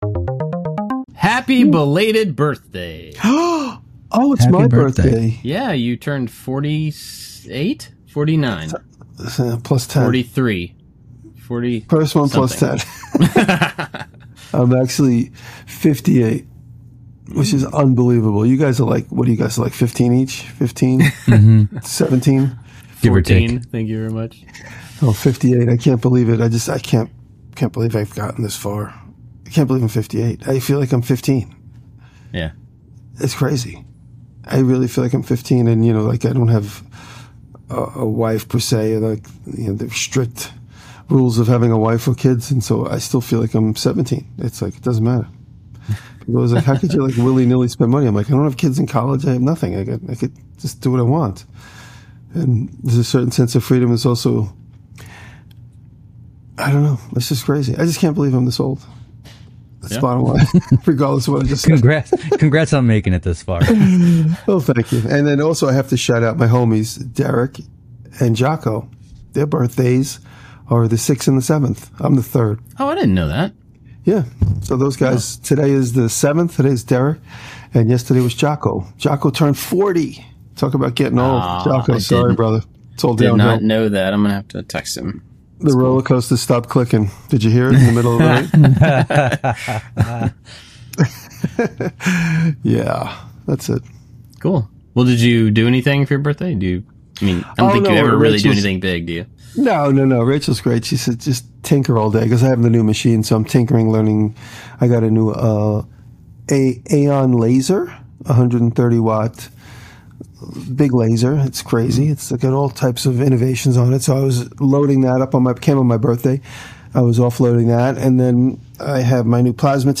Three different makers with different backgrounds talking about creativity, design and making things with your bare hands.